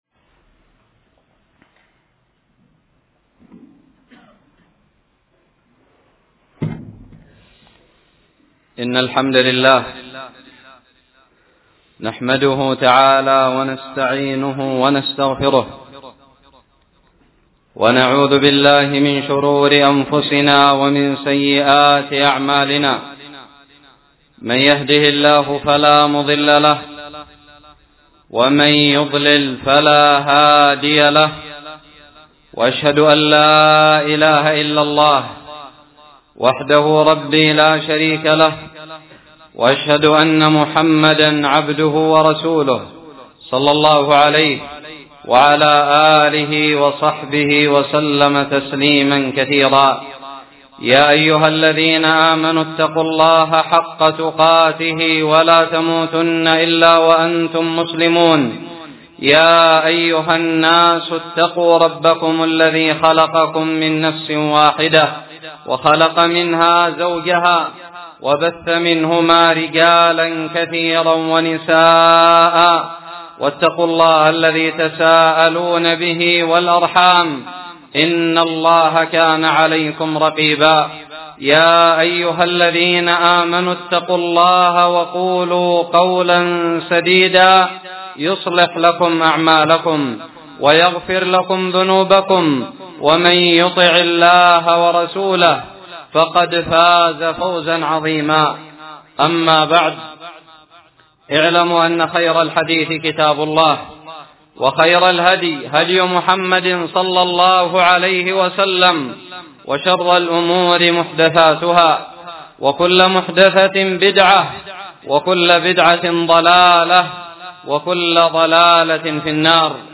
خطب الجمعة
ألقيت بدار الحديث السلفية للعلوم الشرعية بالضالع في 11 جمادى الآخرة 1438هــ